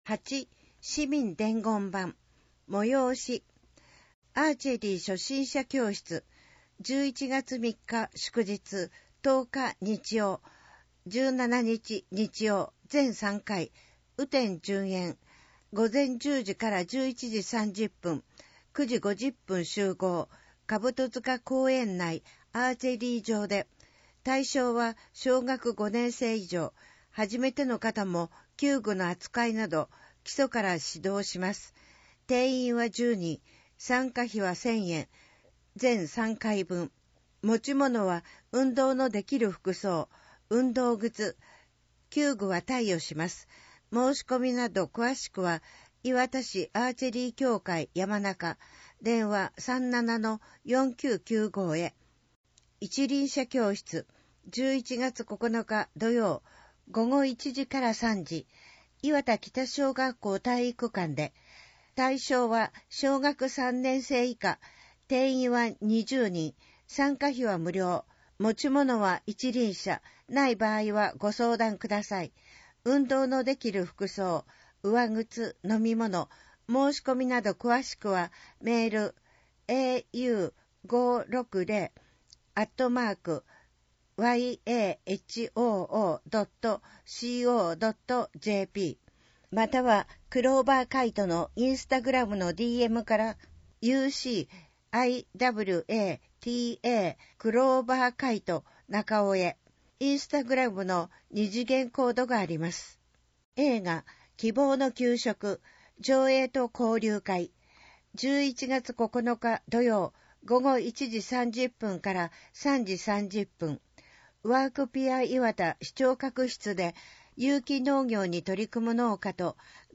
市内の視覚に障がいがある方のために、広報いわたの内容を録音した「声の広報」を制作していますが、声の広報をもとにインターネット上でも手軽に利用できるサイトとして公開しています。